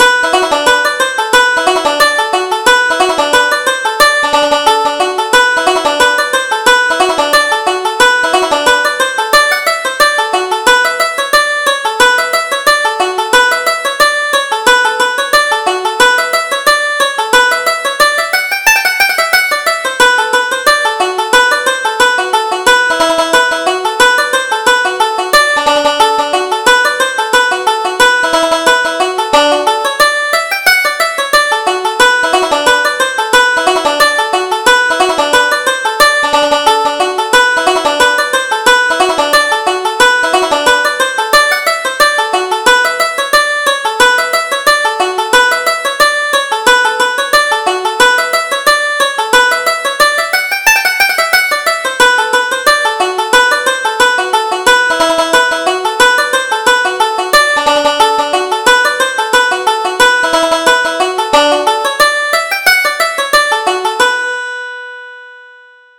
Reel: The Merry Sisters